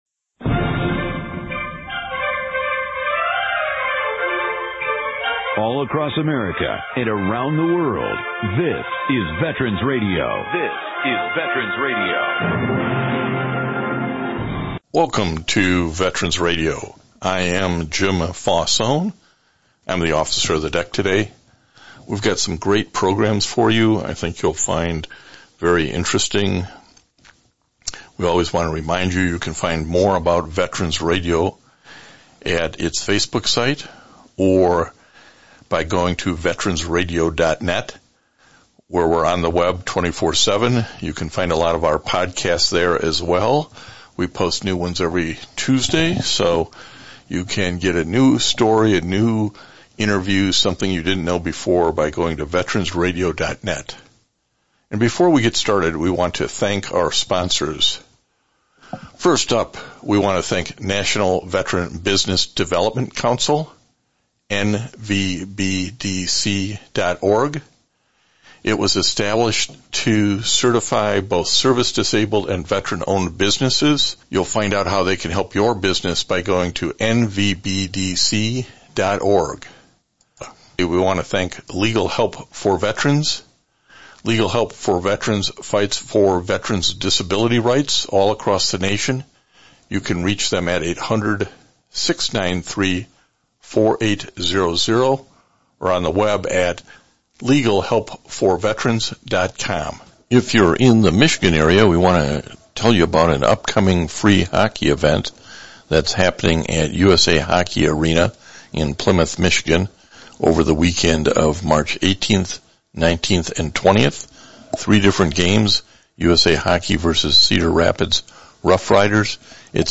March 13, 2022 This week’s one hour radio broadcast is pre-recorded.